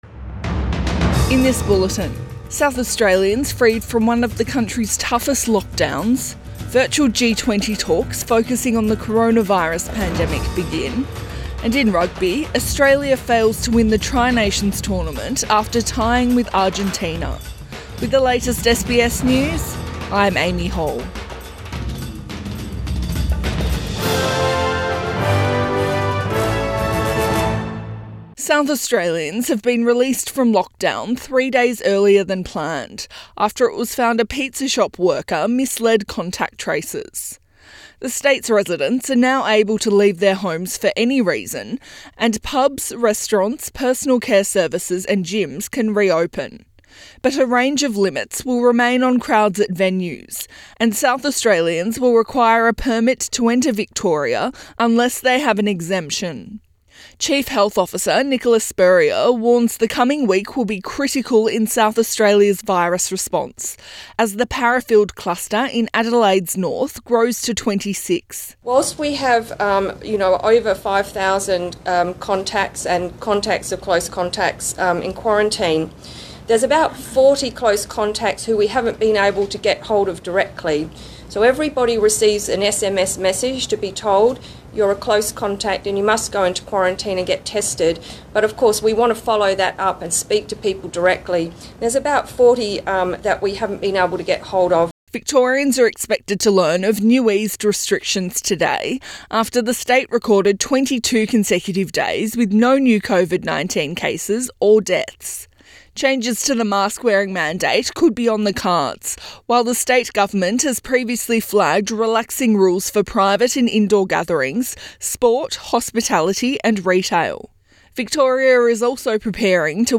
AM bulletin 22 November 2020